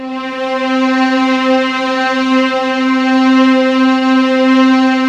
WARM VIOL LM 25.wav